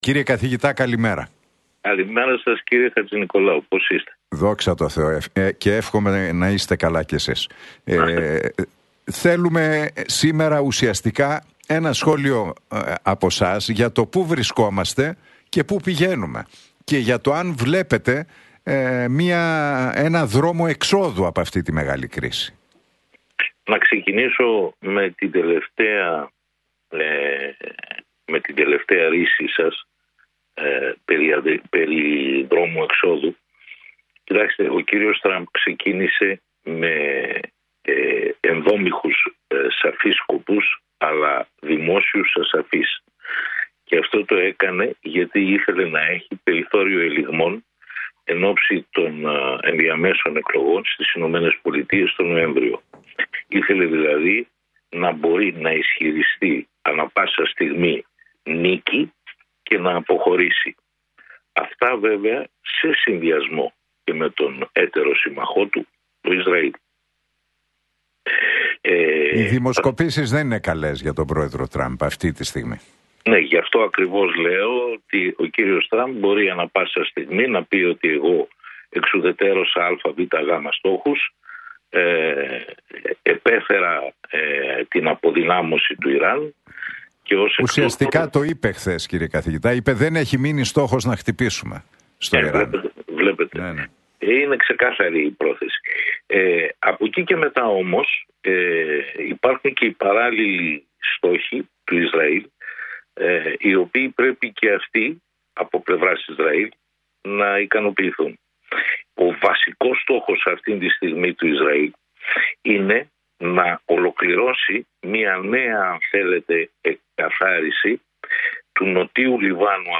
μιλώντας στην εκπομπή του Νίκου Χατζηνικολάου στον Realfm 97,8.